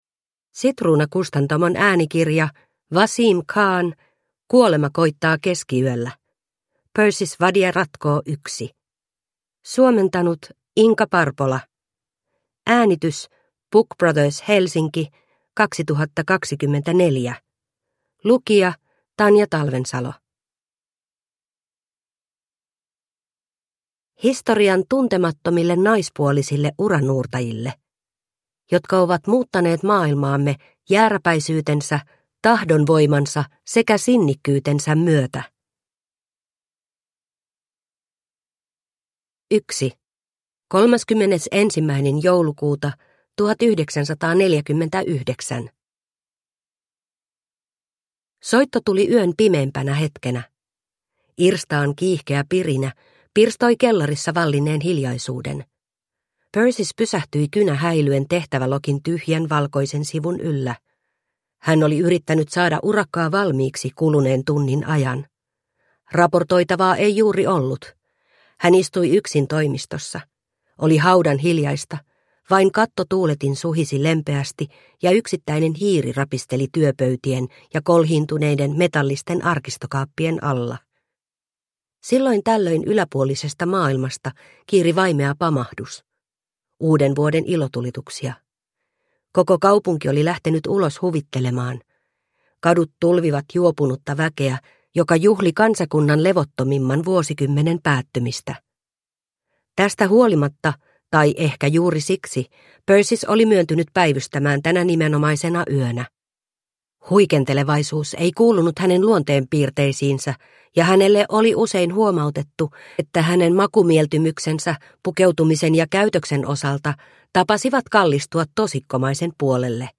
Kuolema koittaa keskiyöllä (ljudbok) av Vaseem Khan